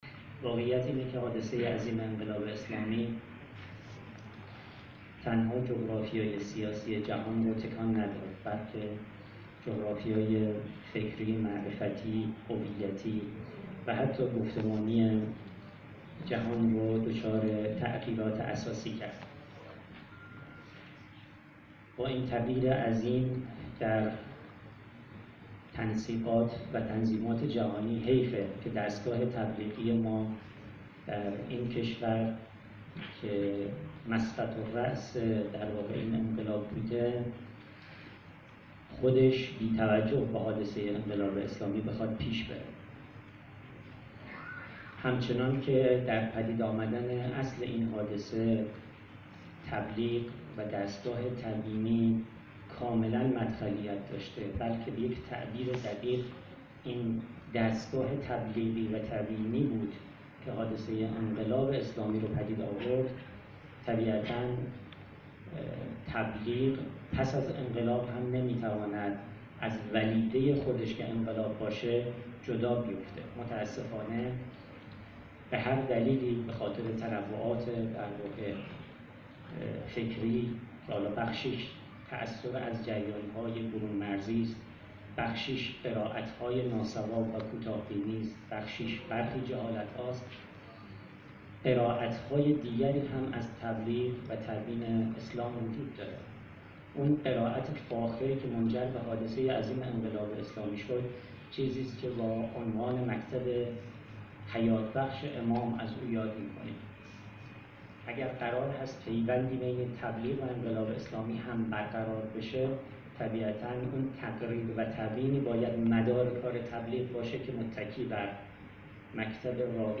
سازمان تبلیغات اسلامی امشب در همایش «گفتمان تبلیغی فاطمیه» که در سالن همایش های اداره کل تبلیغات اسلامی استان قم برگزار شد، ضمن عرض خیر مقدم به حاضران در این جلسه گفت